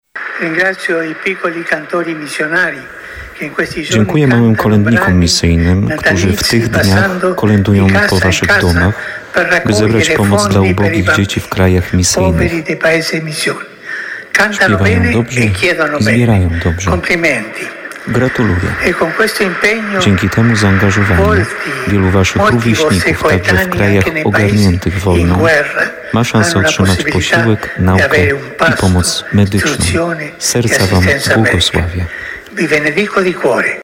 Ojciec Święty podziękował Kolędnikom Misyjnym za to, że pomagają ubogim dzieciom w krajach misyjnych. Papież Franciszek mówił o tym w pozdrowieniach do pielgrzymów polskich podczas środowej audiencji generalnej.